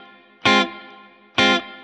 DD_StratChop_130-Fmin.wav